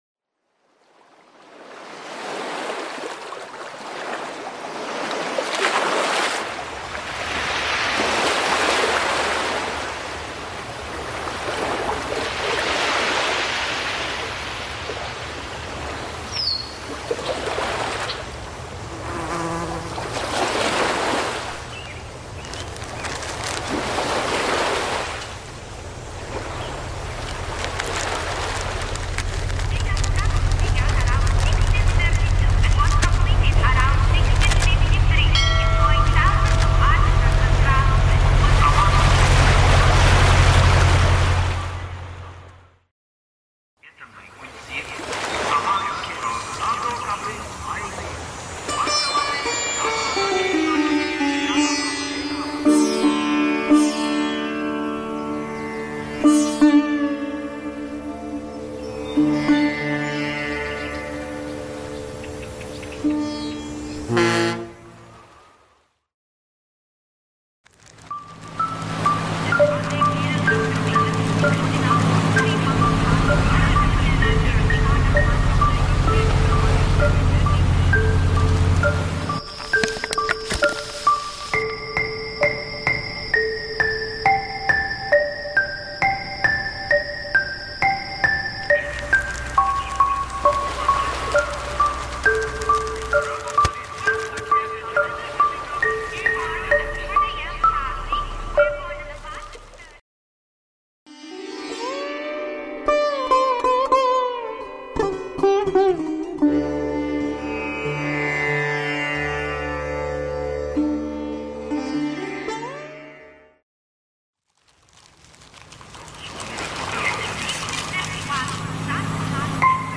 Chinti-paysage-sonore.mp3